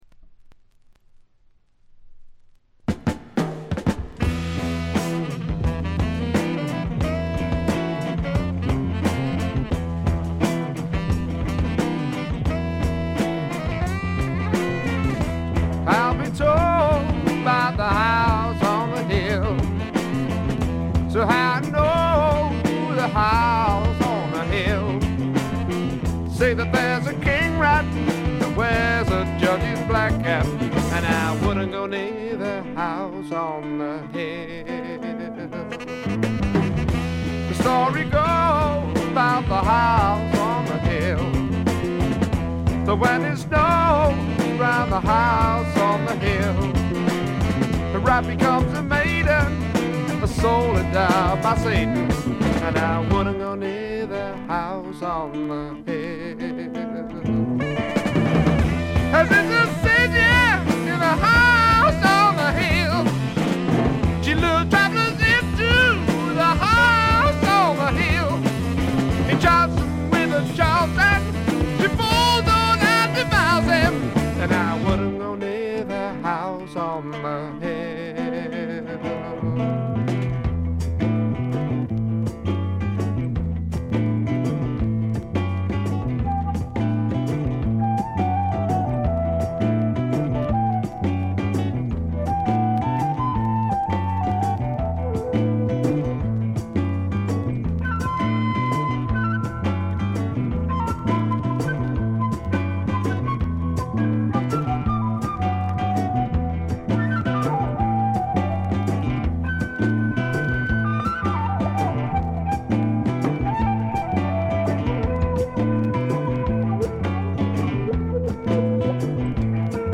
サックスや木管を大胆に導入したアコースティック主体の演奏でジャズ色のある独特のフォーク・ロックを奏でる名作です。
試聴曲は現品からの取り込み音源です。